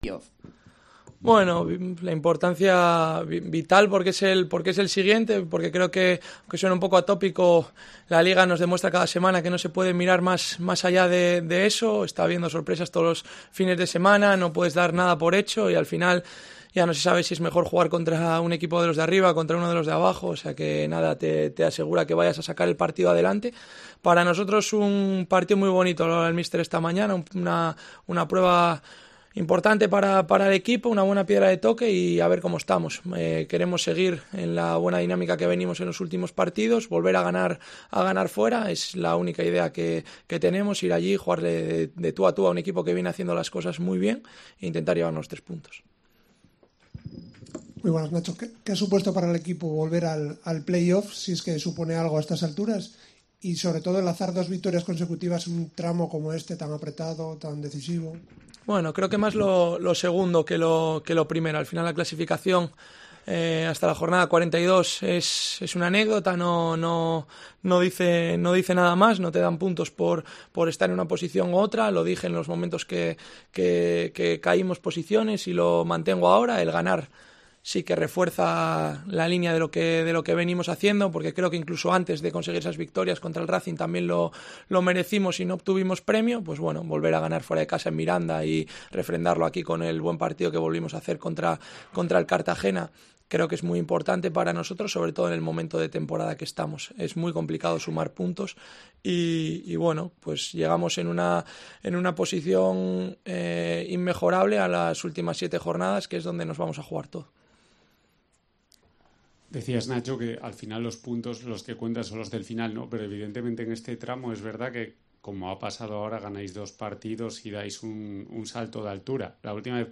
Entrenamiento y rueda de prensa de Nacho Méndez